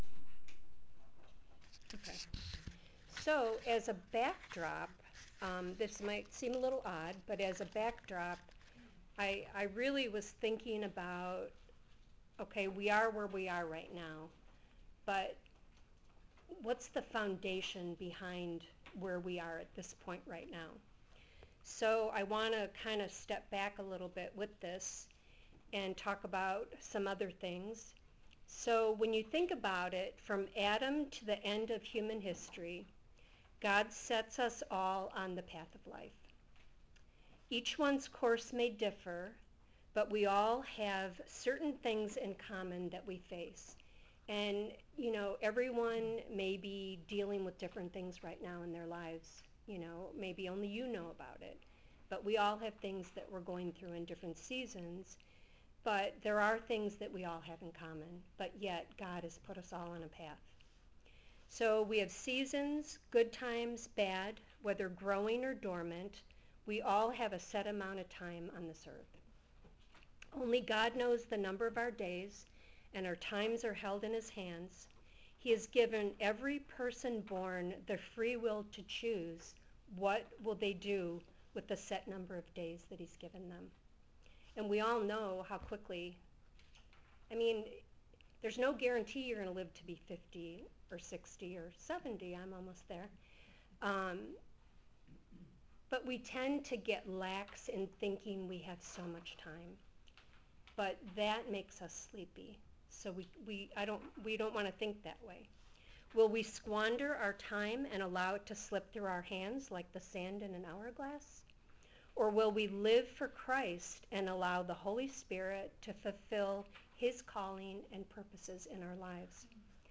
001 - The Kingdom of God (2025 Women’s Conference)